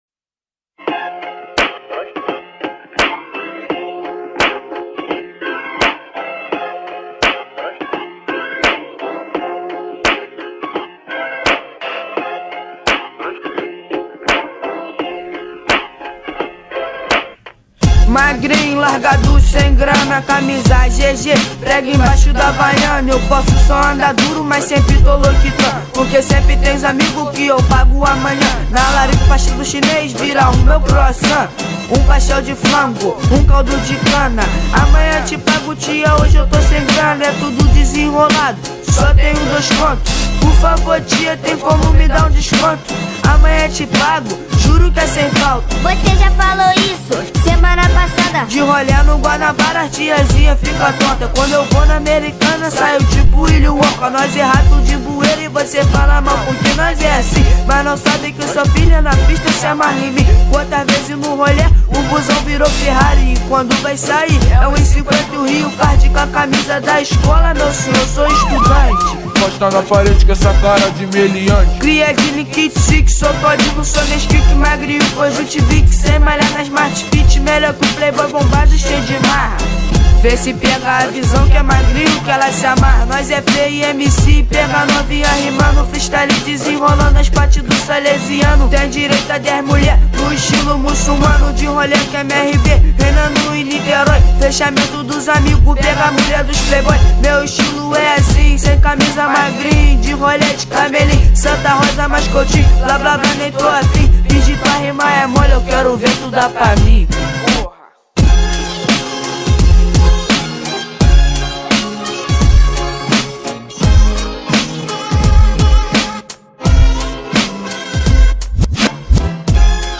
2024-02-20 11:25:08 Gênero: Trap Views